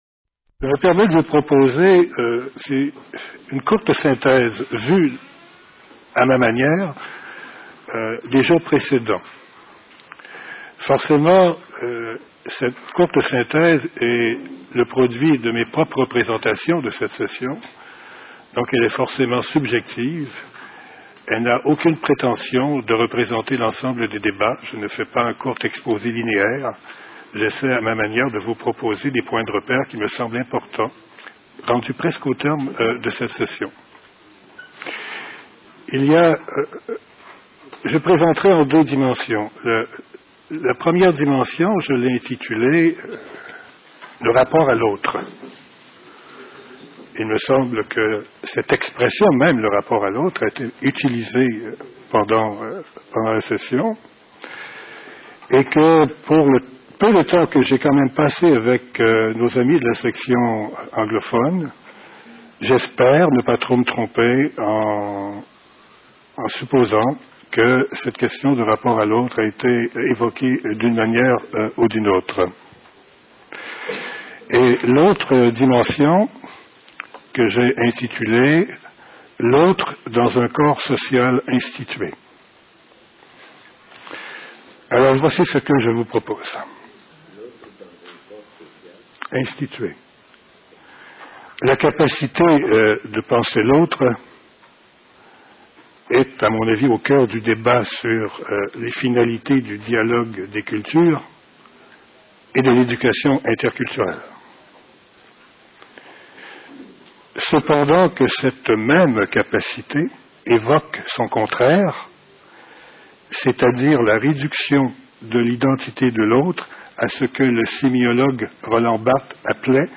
19e session internationale du CIFEDHOP
Genève, du du 8 au 14 juillet 2001